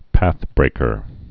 (păthbrākər, päth-)